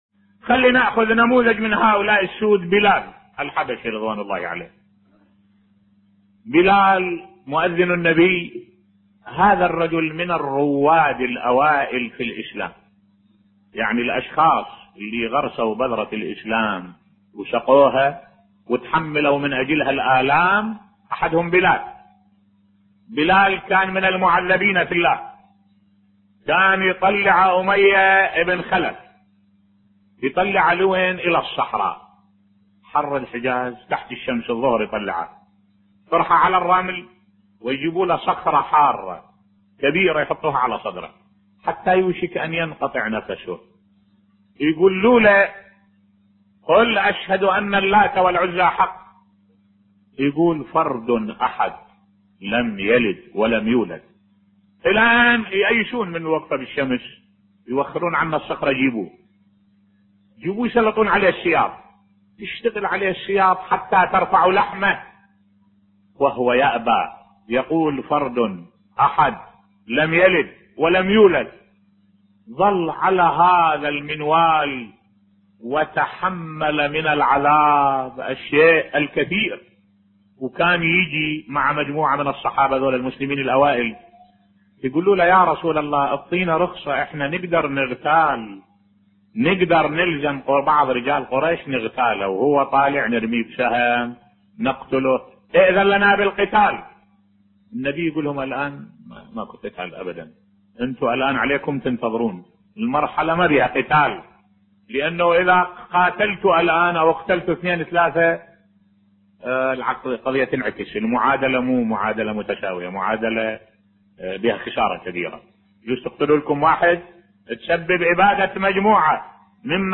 ملف صوتی إشادة بالصحابي الجليل بلال بن رباح بصوت الشيخ الدكتور أحمد الوائلي